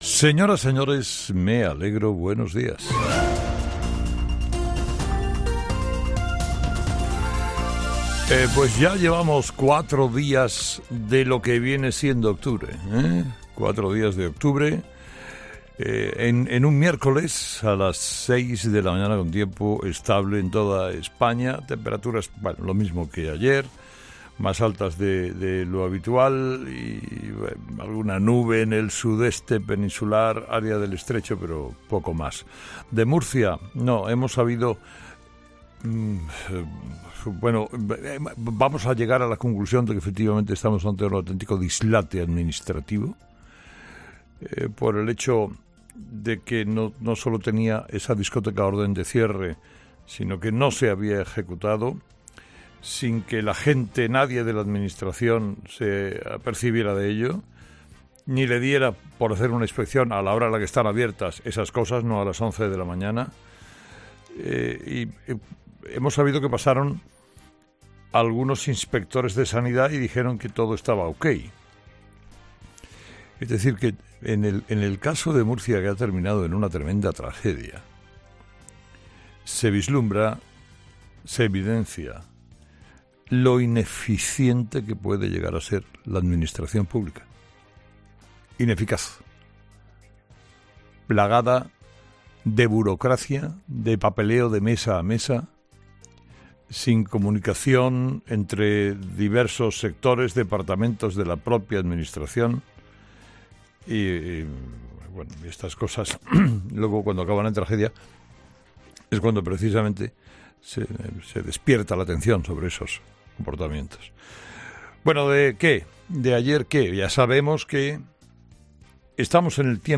AUDIO: Carlos Herrera repasa los principales titulares que marcarán la actualidad de este miércoles 4 de octubre